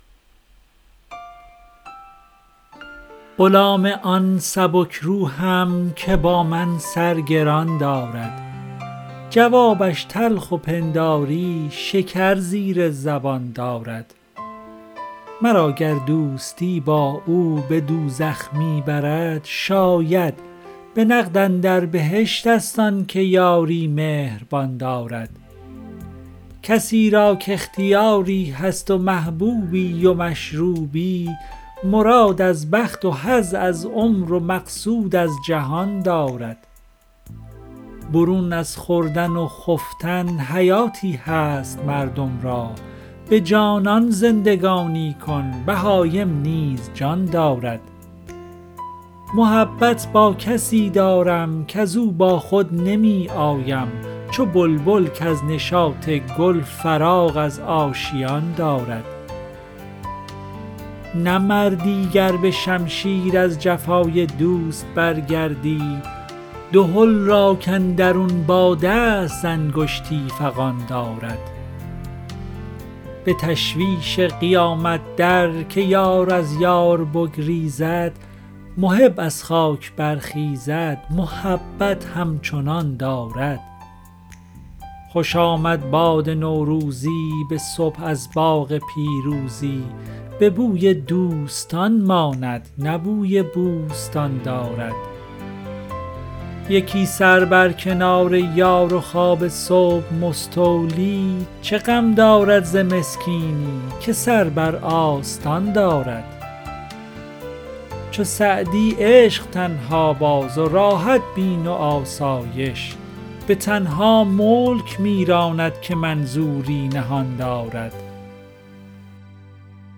سعدی دیوان اشعار » غزلیات غزل ۱۷۰ به خوانش